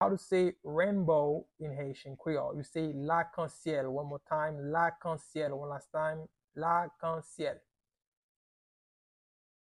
Pronunciation:
Rainbow-in-Haitian-Creole-Lakansyel-pronunciation-by-a-Haitian-teacher.mp3